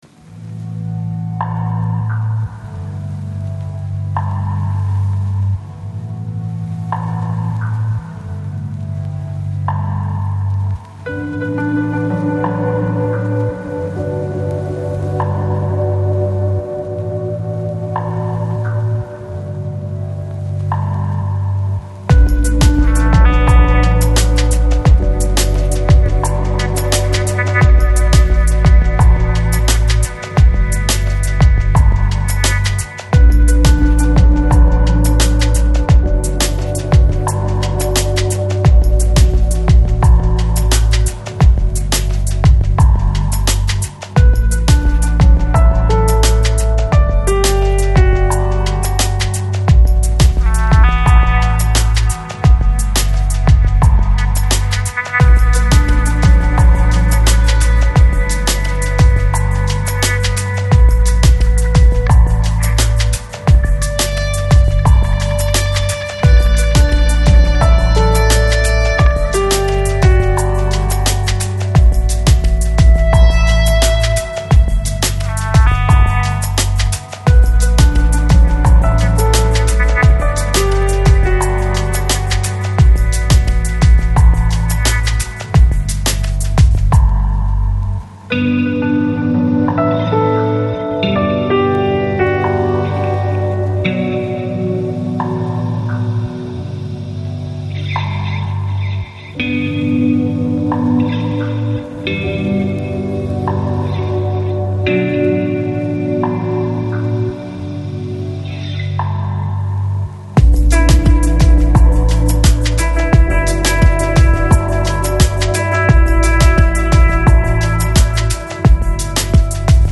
Жанр: New Age, Ambient, Enigmatic, Word